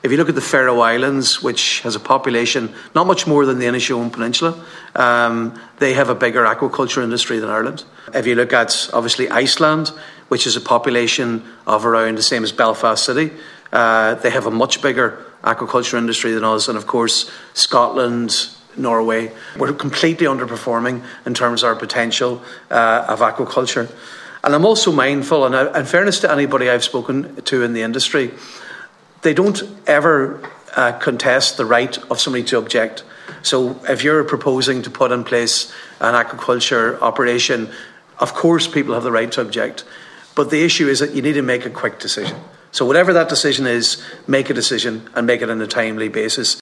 Deputy Padraig MacLochlainn told a meeting of the Oireachtas Fisheries Committee that Ireland is not meeting its potential for aquaculture development.